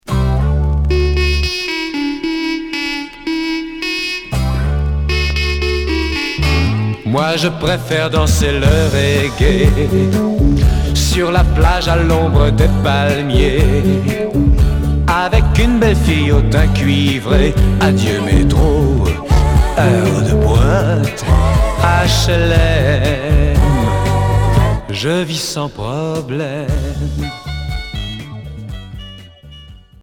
Reggae variété